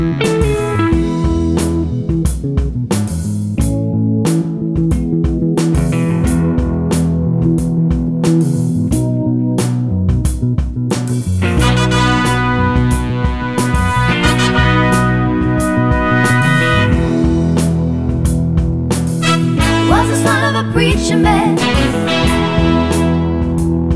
One Semitone Down Pop (1960s) 2:32 Buy £1.50